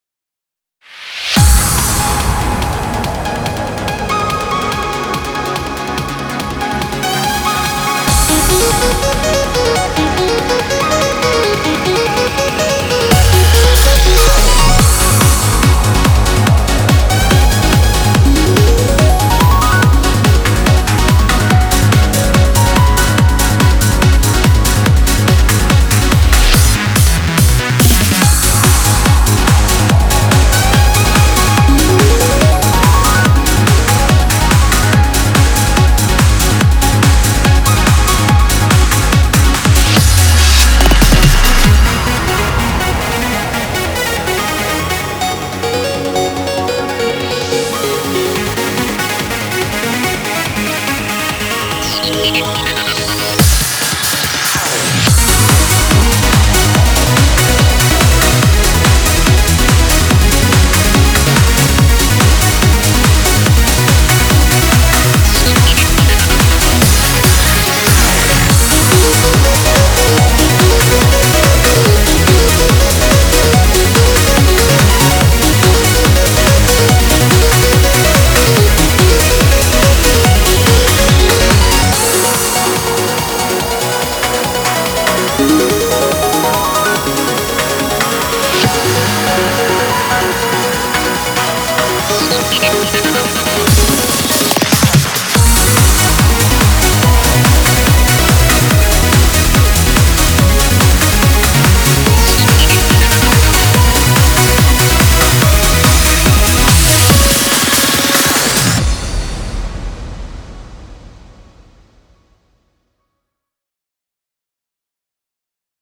BPM143
Comments[UPLIFTING TRANCE]